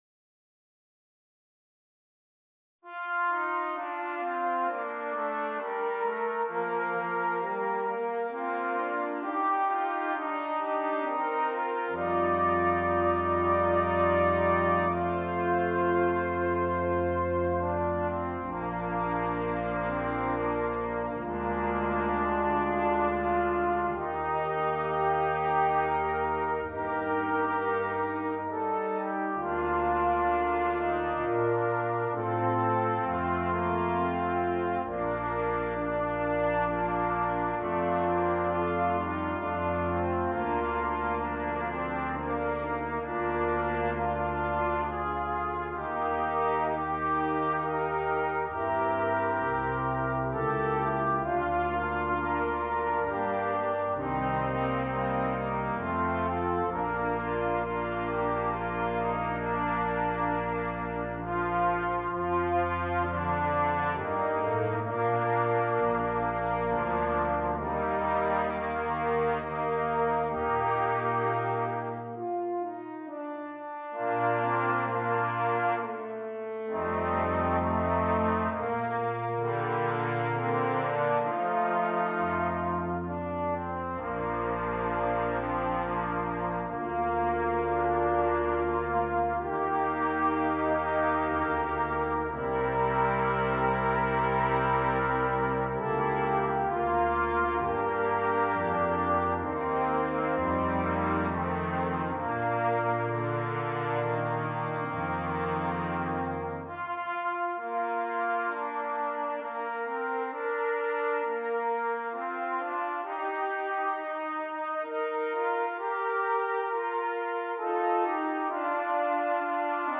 Brass Quintet
Traditional